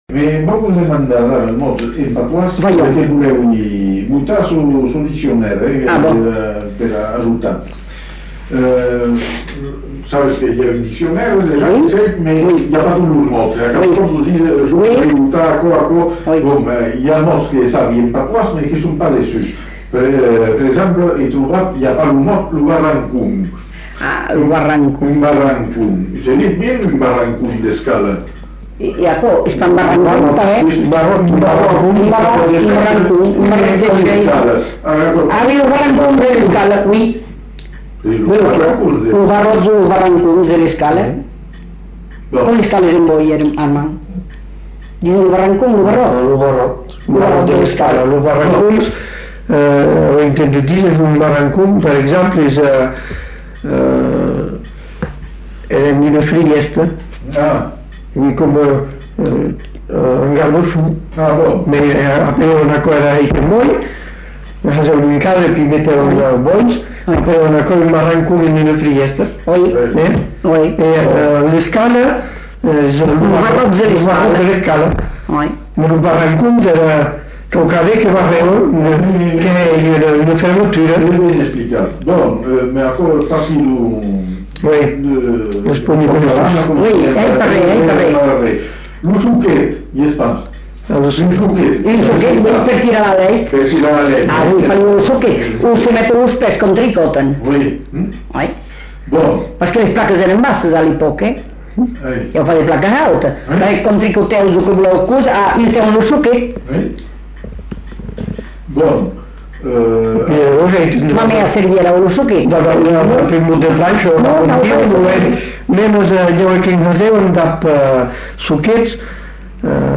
Discussion avec collecte de vocabulaire occitan
Lieu : Bazas
Genre : témoignage thématique